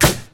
• Smooth Snare Drum Sample G Key 04.wav
Royality free snare single hit tuned to the G note. Loudest frequency: 3291Hz
smooth-snare-drum-sample-g-key-04-Ukn.wav